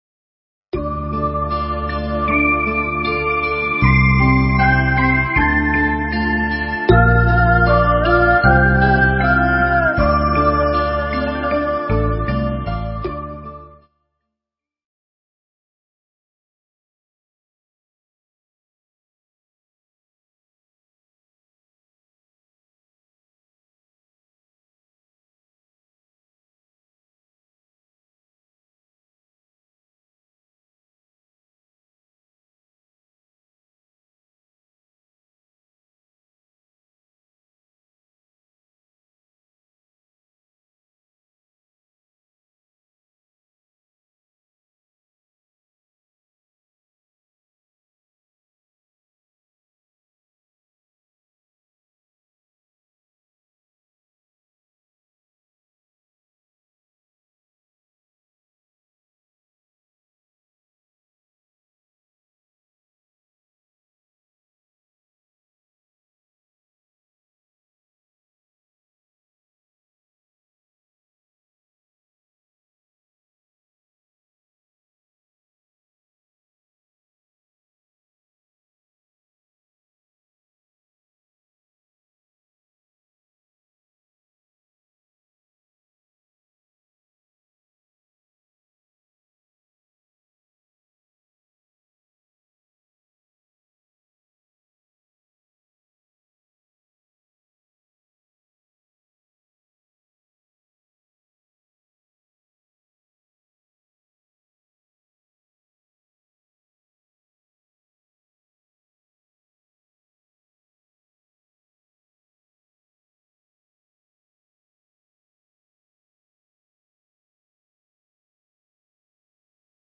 Mp3 Pháp Thoại Tu Là Nguồn Hạnh Phúc – Thầy Thích Thanh Từ nhân dịp Viếng Thăm Chùa Khánh Sơn, Sóc Trăng, ngày 19 tháng 7 năm 2000